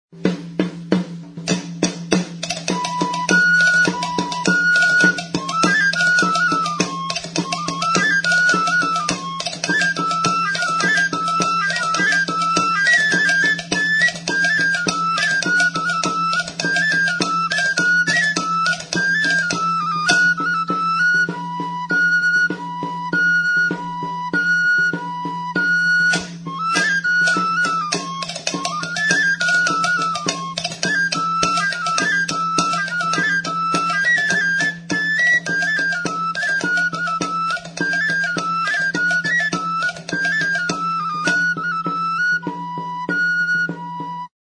Idiófonos -> Golpeados -> Indirectamente
HM udazkeneko kontzertua.